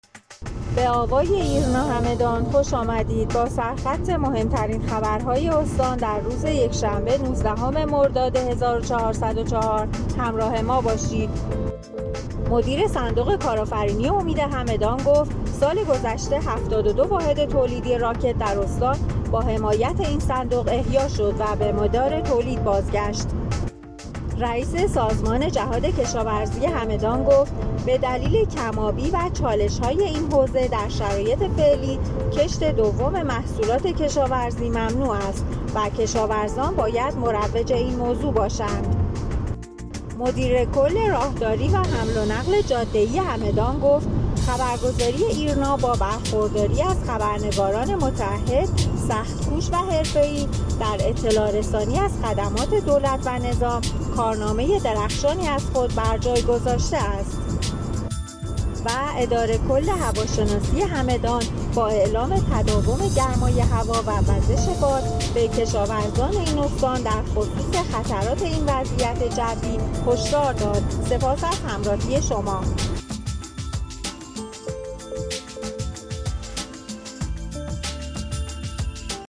همدان-ایرنا- مهم‌ترین عناوین خبری دیار هگمتانه را هر شب از بسته خبر صوتی آوای ایرنا همدان دنبال کنید.